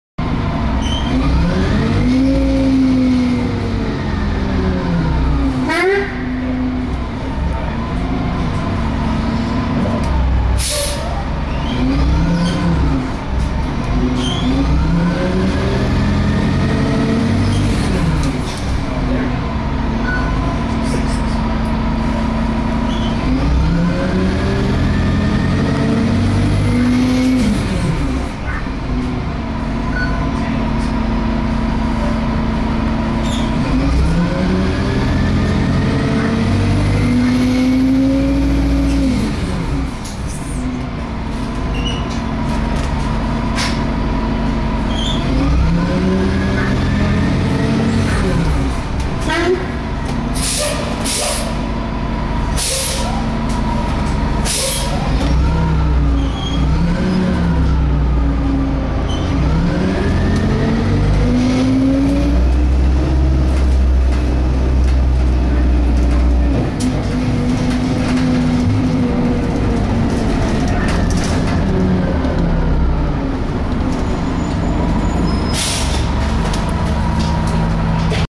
Field Recording #3
-Riding an empty bus to and from the mall. There was no one talking on this bus, only the sound of the engine.
Bus-Sounds.mp3